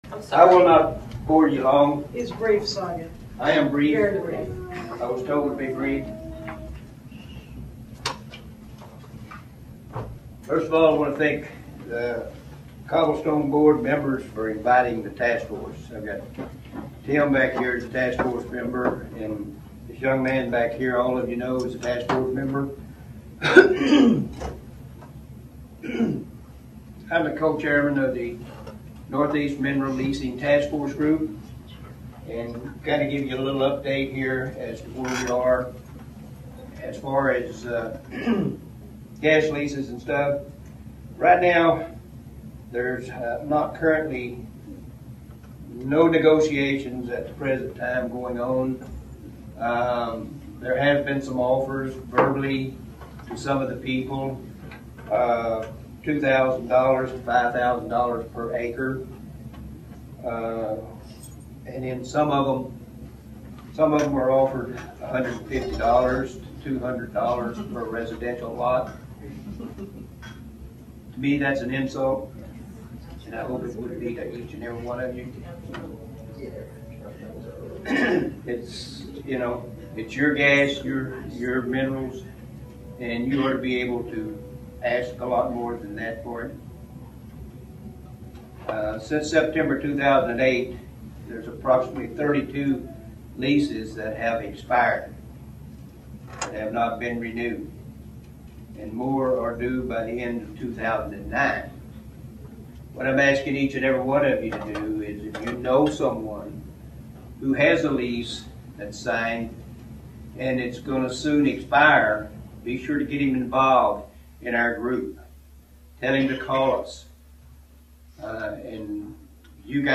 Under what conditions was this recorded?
The following is the audio from the Cobblestone meeting on 04/07/09 discussing the gas drilling leases.